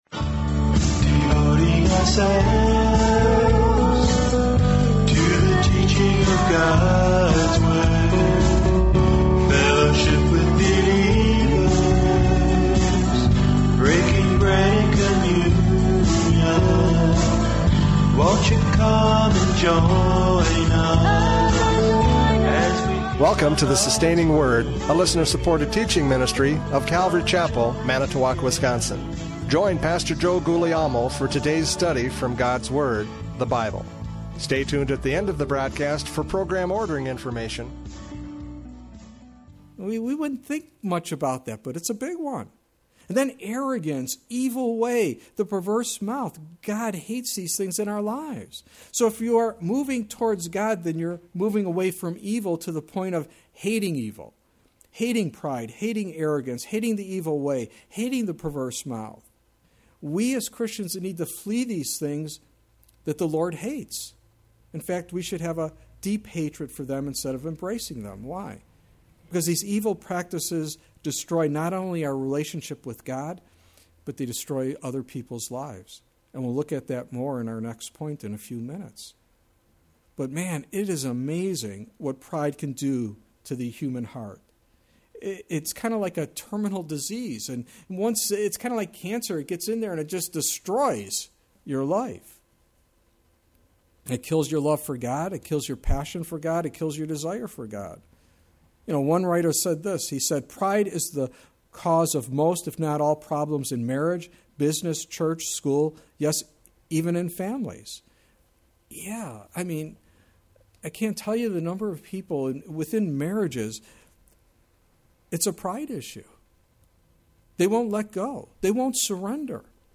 Judges 14:12-18 Service Type: Radio Programs « Judges 14:12-18 The Pride of Samson!